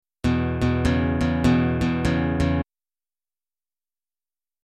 E5 to E6 (The I Chord)
E5 to E6 Shuffle
e5toe6shuffle.mp3